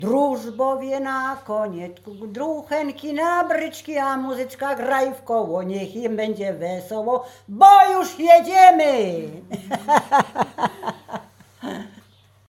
Łęczyckie
Weselna
weselne na wyjazd do kościoła